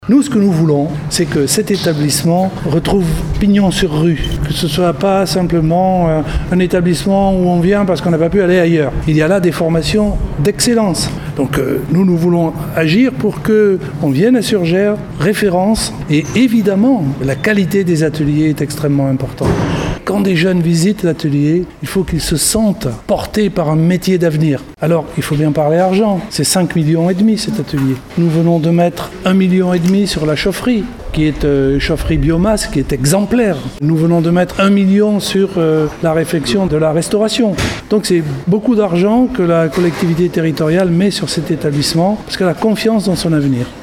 Plusieurs millions d’euros sont engagés pour la rénovation de l’établissement spécialisé dans les métiers du bois et de la vente, afin d’avoir un outil de formation performant et des conditions d’enseignement modernes pour attirer les jeunes. C’est ce qu’est venu confirmer et constater sur place hier le vice-président en charge de l’éducation Jean-Louis Nembrini :